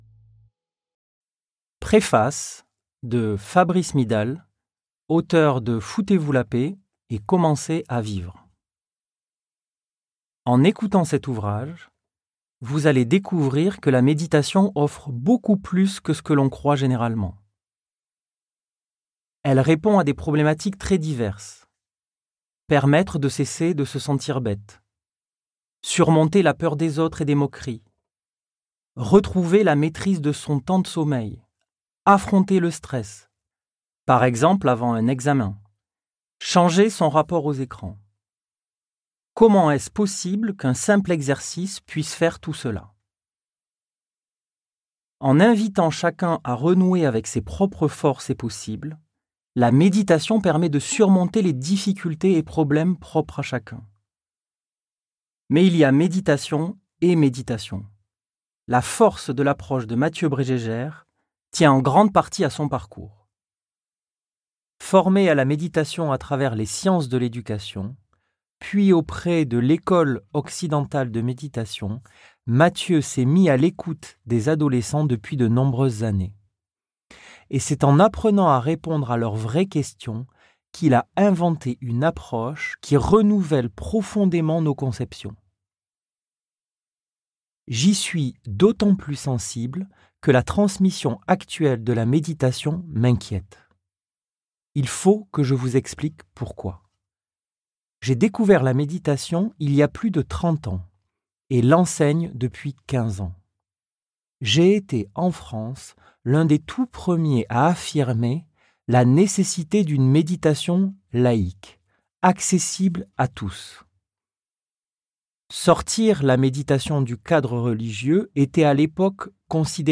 Get £2.40 by recommending this book 🛈 Un livre audio indispensable pour tous les adolescents d'aujourd'hui.